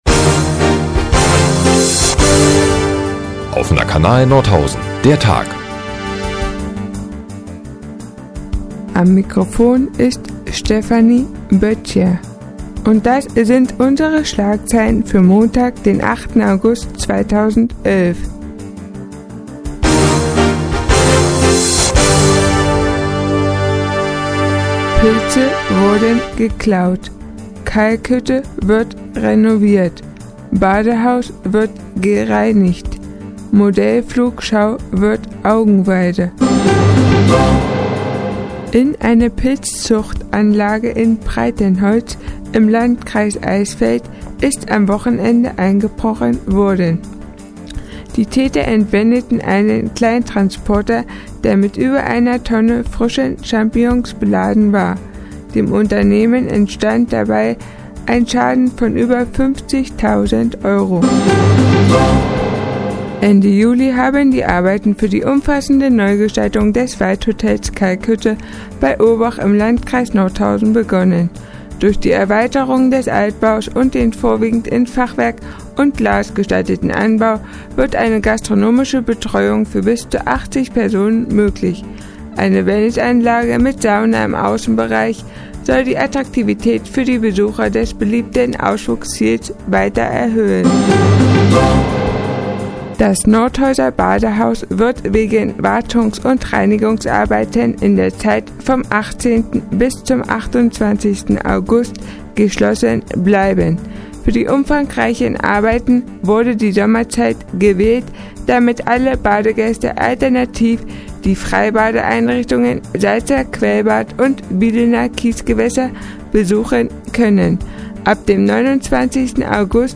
Die tägliche Nachrichtensendung des OKN ist nun auch in der nnz zu hören. Heute geht es um gestohlene Pilze, Modellflieger und mehrere Bademöglichkeiten.